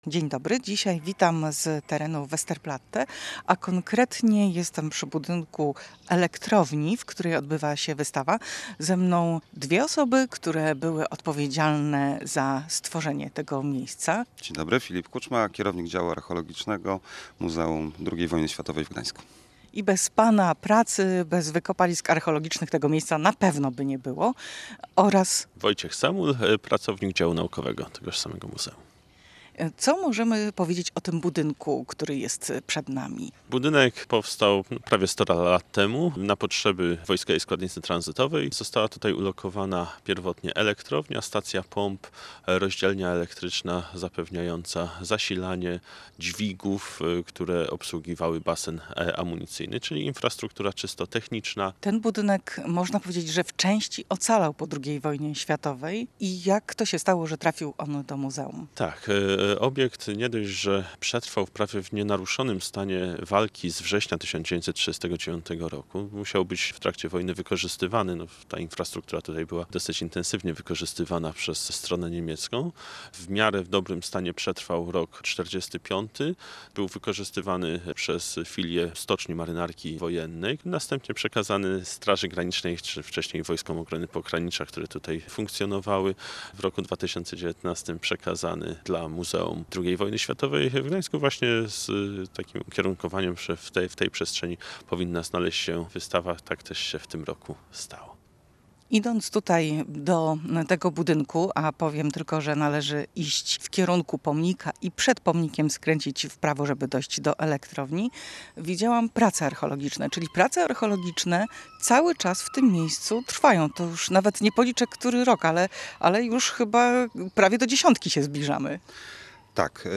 Losy Westerplatte i znalezione artefakty znalazły się na wystawie w dawnym budynku elektrowni, który zwiedziła z mikrofonem